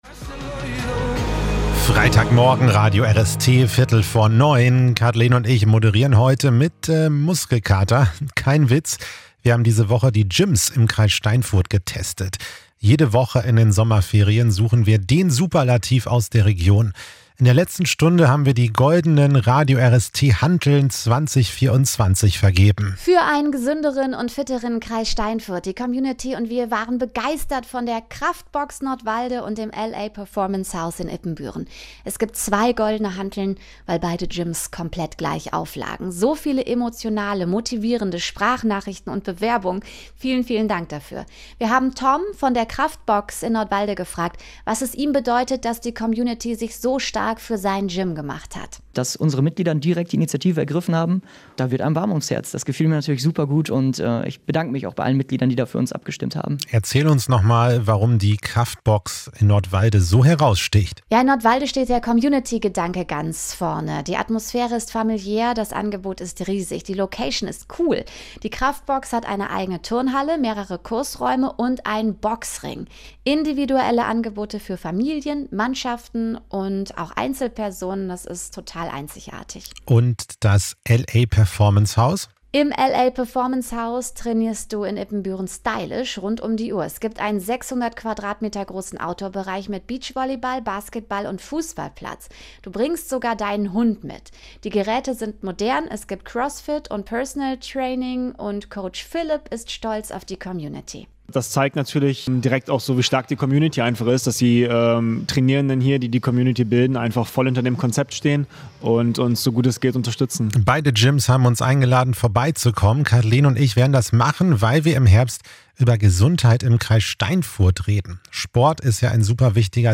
Gewinnertalk 2